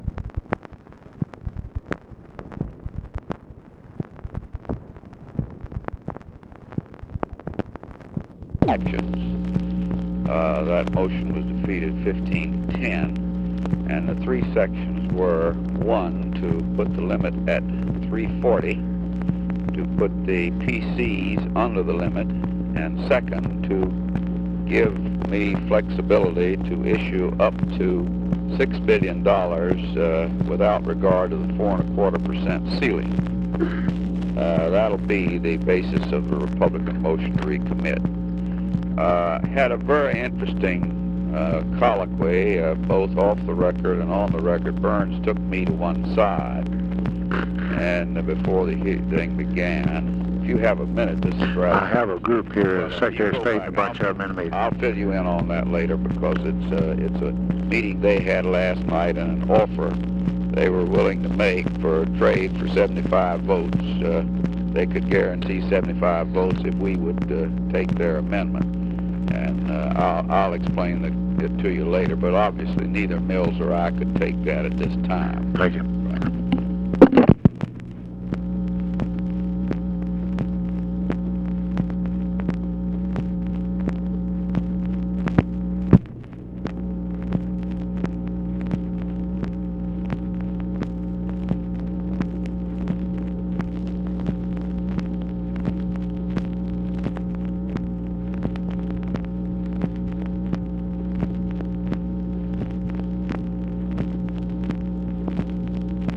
Conversation with HENRY FOWLER, February 2, 1967
Secret White House Tapes